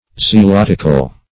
Search Result for " zealotical" : The Collaborative International Dictionary of English v.0.48: Zealotical \Zea*lot"ic*al\, a. Like, or suitable to, a zealot; ardently zealous.